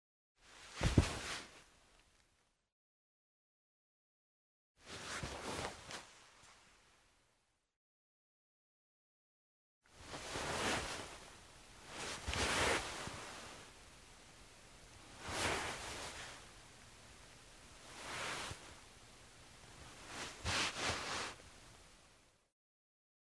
Шум передвигаемой мебели